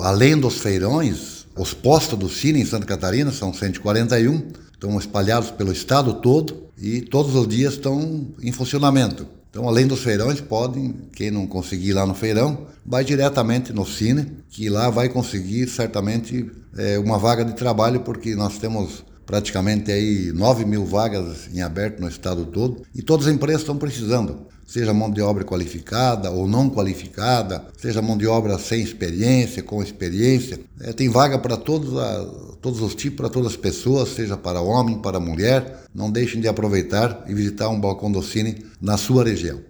Para o secretário de Estado de Indústria, Comércio e Serviço, Silvio Dreveck, a oferta de vagas no Sine é reflexo do bom momento da economia catarinense. Ele destaca que, além dos feirões que estão sendo realizados, os mais de 140 postos do sine estão a disposição da população: